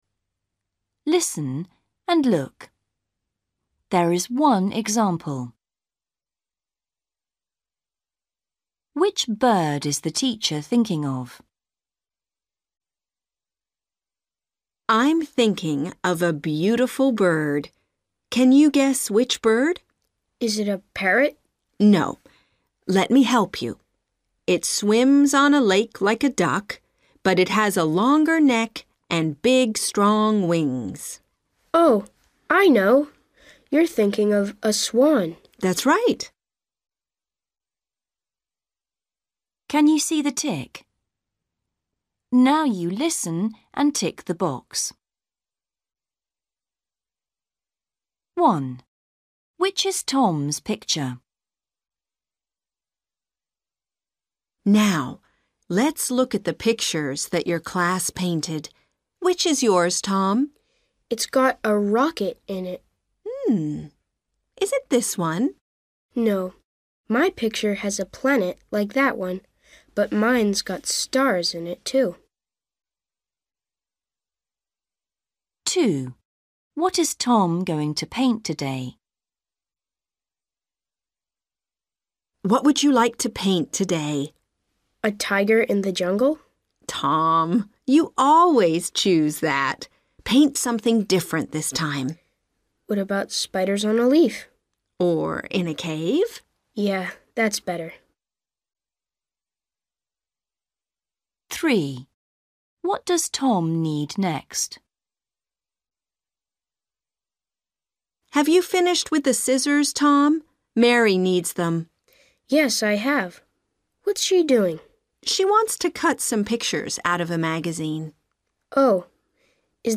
Listening: Everyday Conversations and Activities